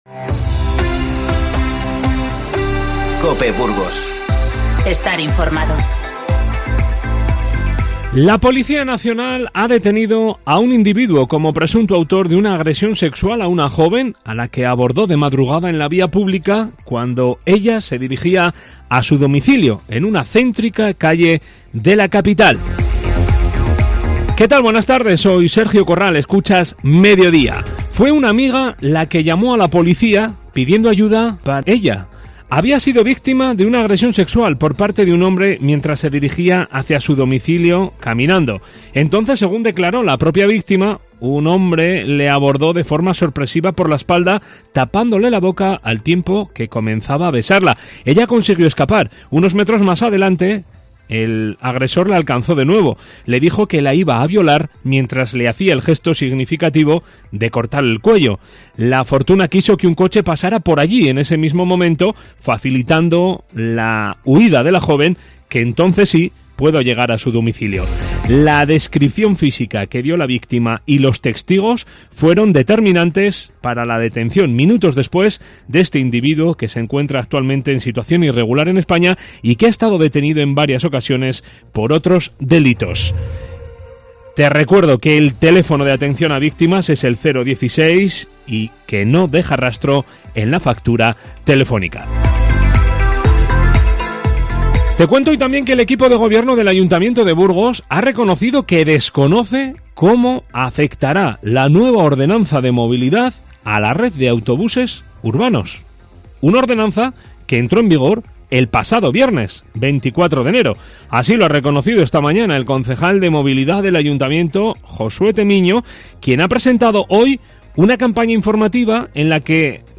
Informativo 29-01-20